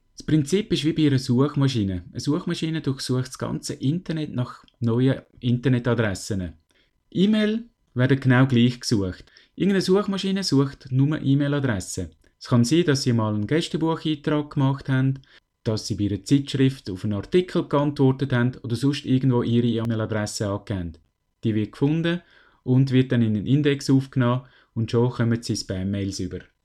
Fragen, die uns jetzt ein Experte beantworten kann.